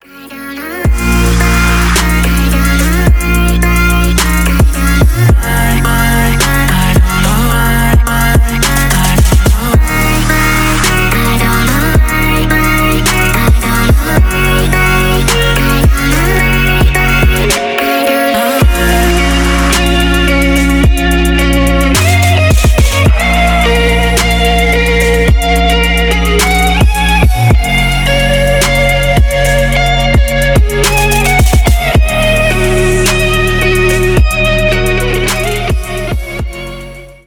бесплатный рингтон в виде самого яркого фрагмента из песни
Электроника
без слов